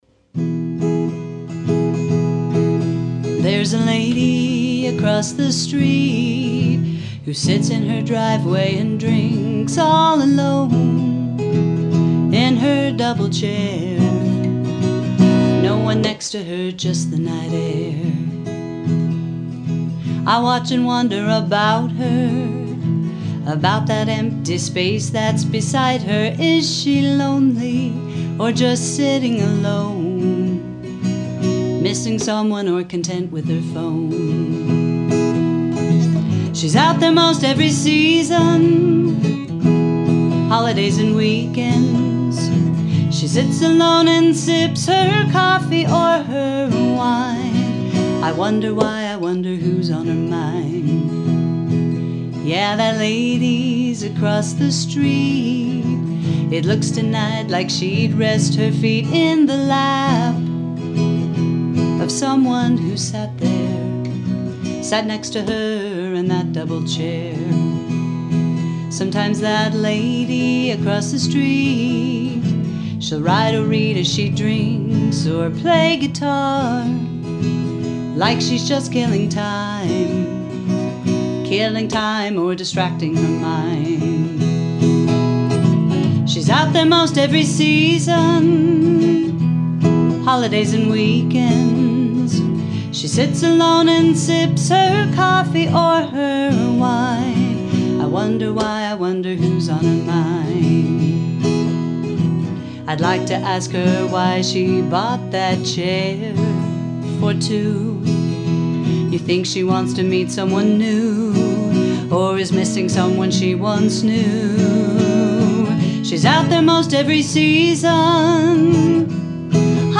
Here are some simple recordings of the original songs mentioned in the book – just to give you an idea of what they sound like: